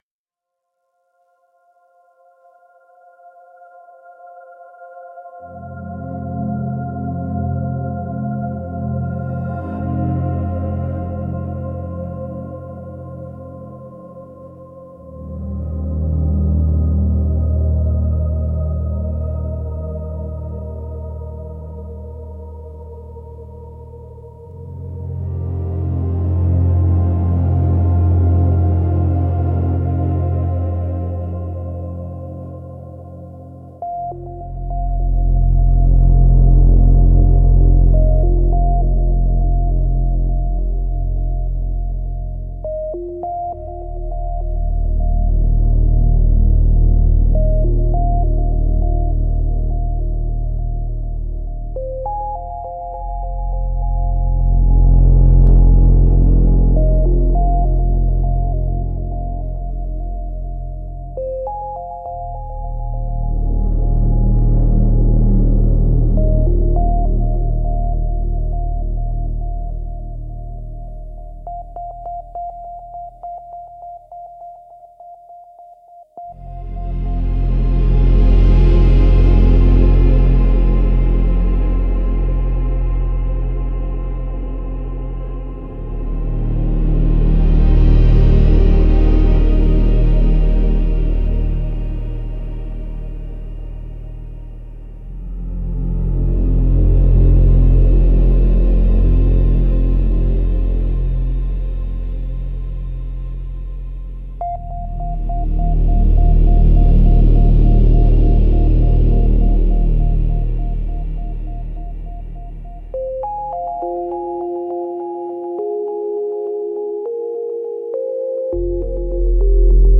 Space-Ambient-Album